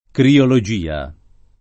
[ kriolo J& a ]